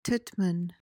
PRONUNCIATION: (TIT-muhn) MEANING: noun : 1.